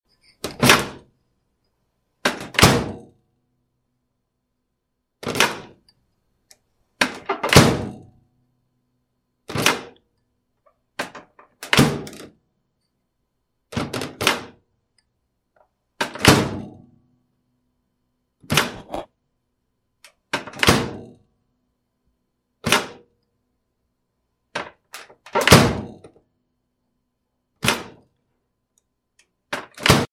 Microwave Door Open Close Sound